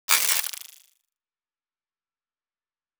sound-print-photo.wav